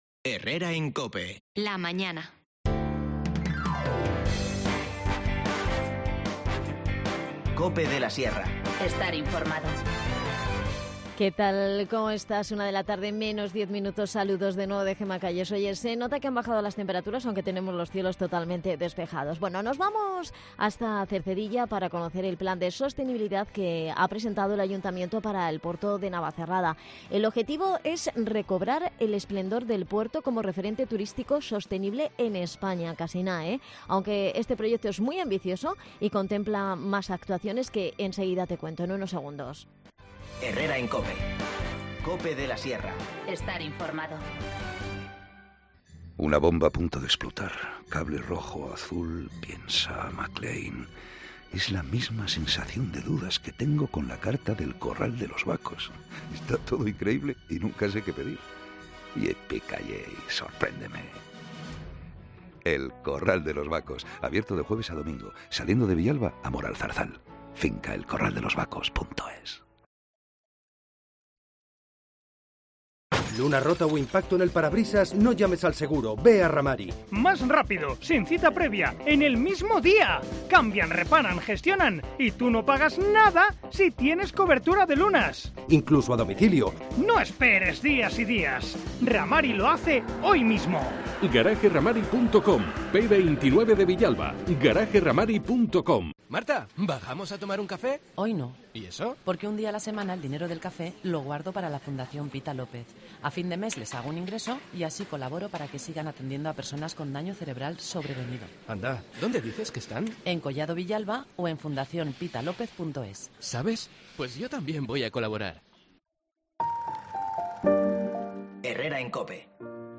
Cercedilla presenta un plan de sostenibilidad turísta para el Puerto de Navacerrada. El objetivo es recobrar el esplendor de este enclave como referente de turismo sostenible en España. Hablamos con Isabel Pérez Montalvo, concejal de Desarrollo Local